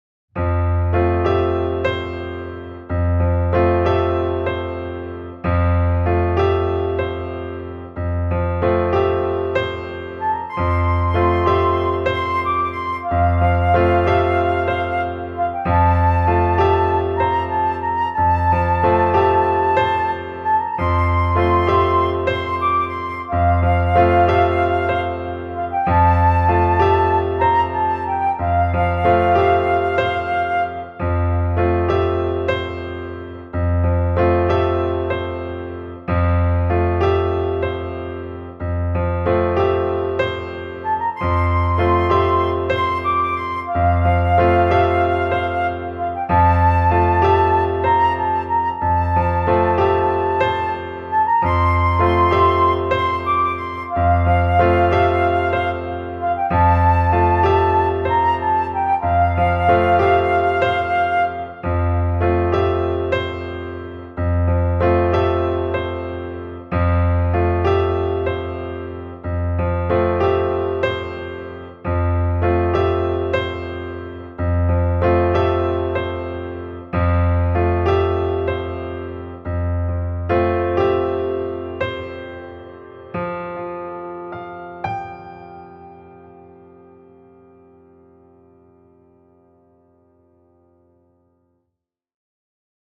ピアノとフルートのみを使用したシンプルな構成の曲です。